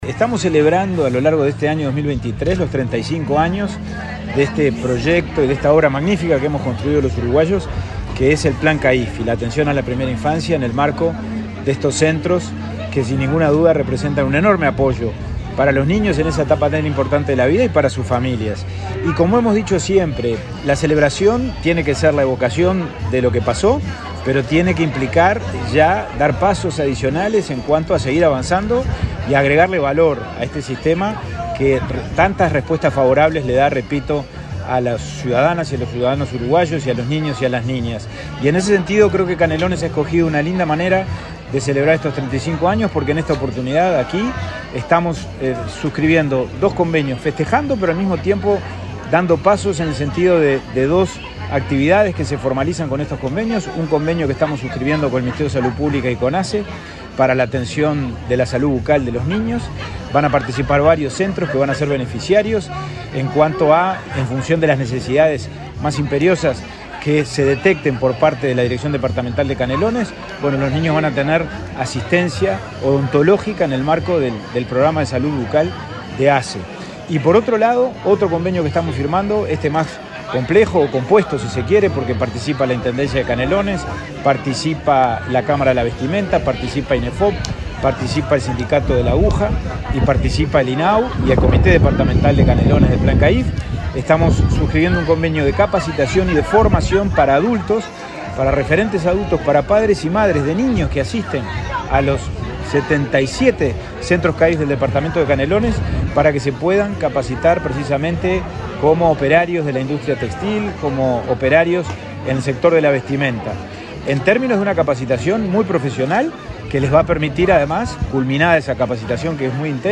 Declaraciones del presidente del INAU, Pablo Abdala
Declaraciones del presidente del INAU, Pablo Abdala 26/07/2023 Compartir Facebook X Copiar enlace WhatsApp LinkedIn El presidente del Instituto del Niño y el Adolescente del Uruguay (INAU), Pablo Abdala, dialogó con la prensa en Canelones, antes de participar en la firma de varios convenios por temas de vestimenta y salud bucal.